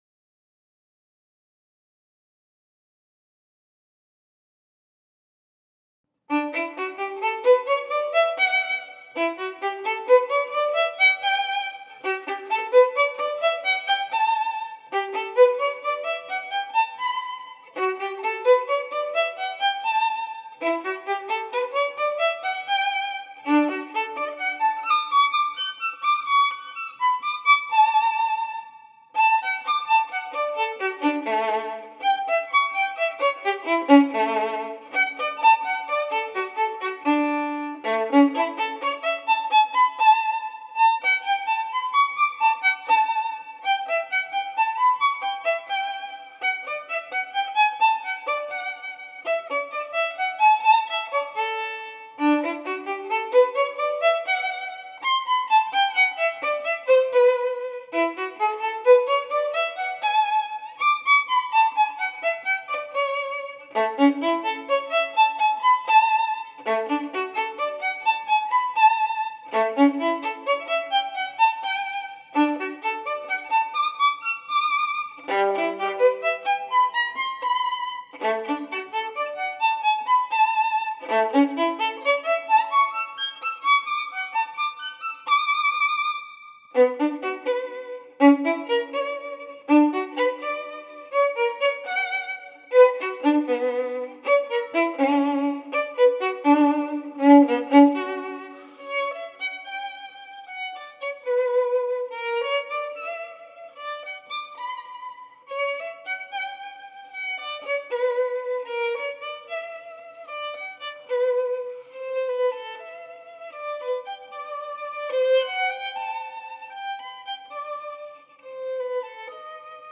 Alumno de Baillot continuó con los grandes métodos de violín de la escuela francesa.